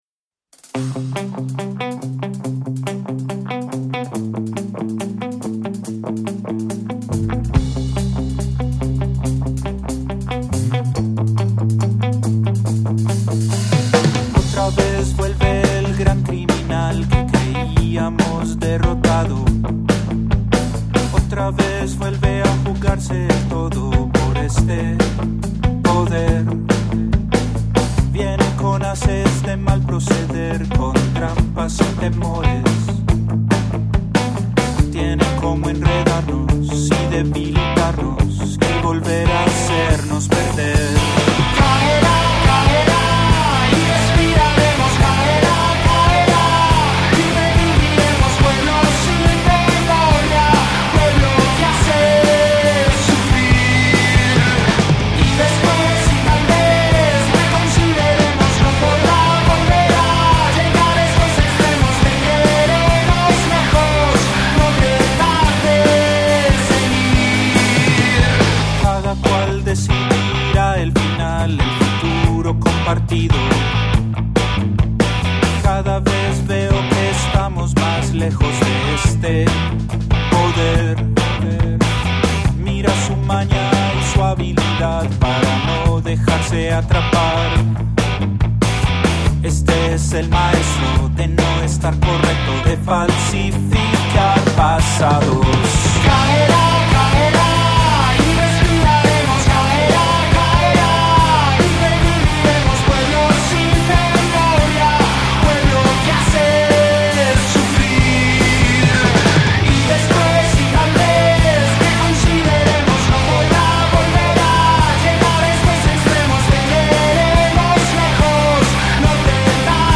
Rock and roll